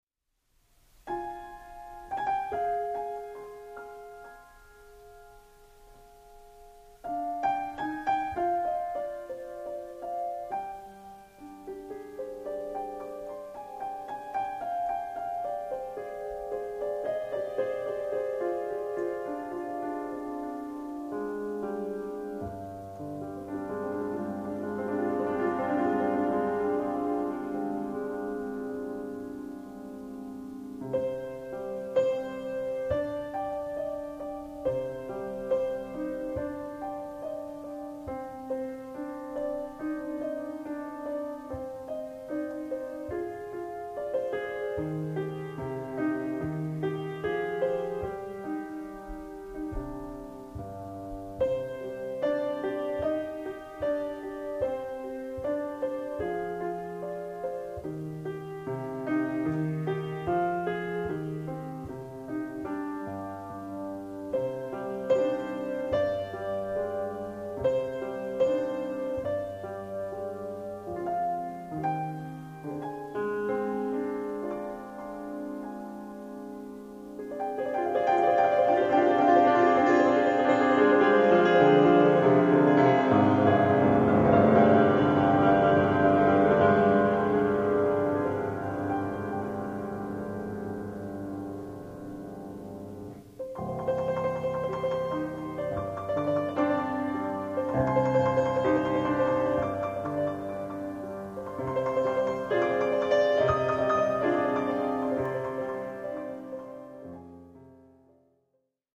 日本古謡
ピアノ